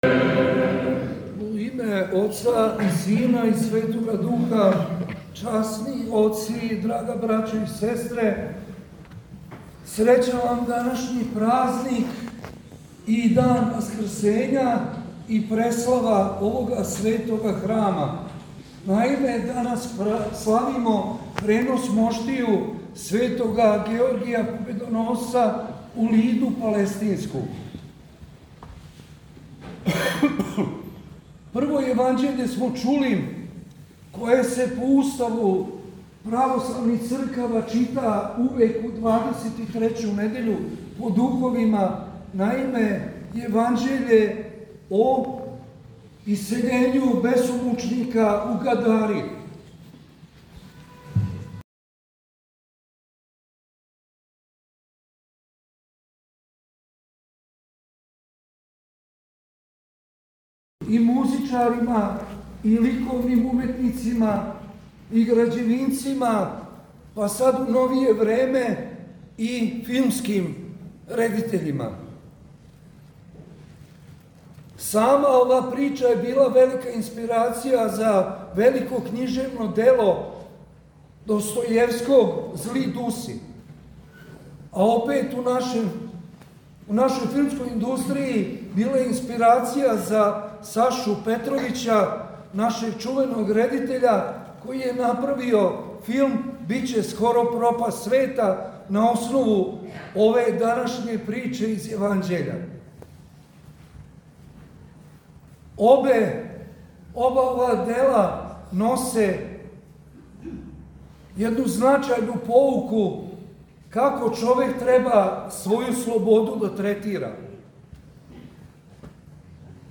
У оквиру серијала „Са амвона“, доносимо звучни запис беседе коју је Његово Преосвештенство Епископ јенопољски г. Никон, викар Патријарха српског, изговорио у недељу двадесет и трећу по празнику свете Педесетнице, а на дан спомена Преноса моштију Светог великомученика и победоносца Георгија, 3/16. новембра 2025. године. Епископ Никон је беседио на светој Литургији у Светогеоргијевском храму на Бановом Брду.